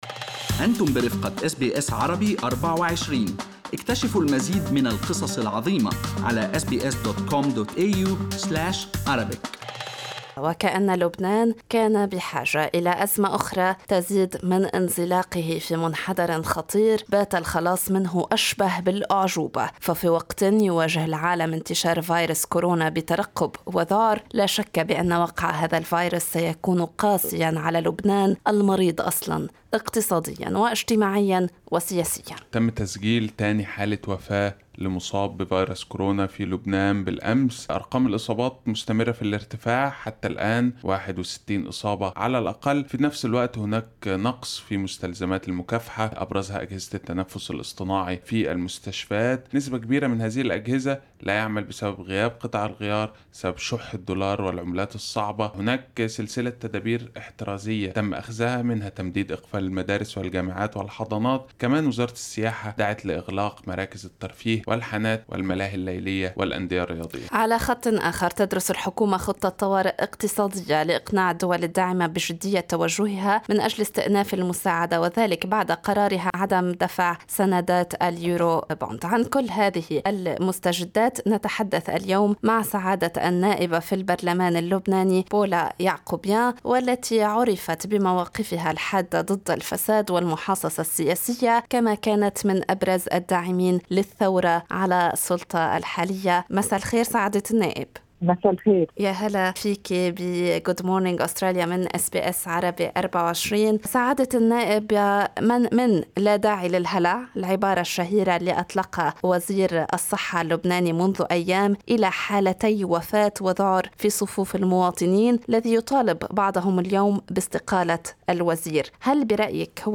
وفي حديث خاص مع أس بي أس عربي 24، انتقدت يعقوبيان الإهمال والتقاعس اللذين أظهرتهما الدولة اللبنانية في تعاطيها مع أزمة فيروس كورونا.